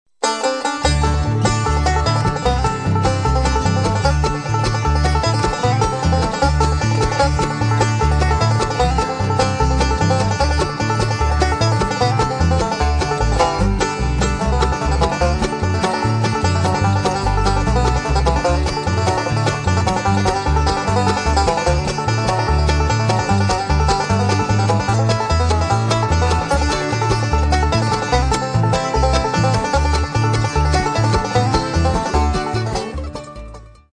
Double Bass, Vocals
Banjo, Vocals
Mandolin, Fiddle, Theramin, Vocals
some of the best bluegrass playing the country has to offer